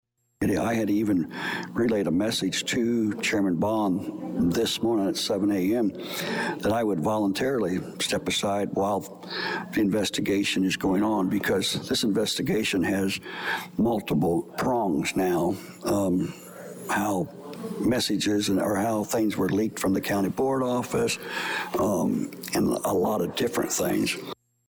(Board member Jerry Hawker – in tan vest)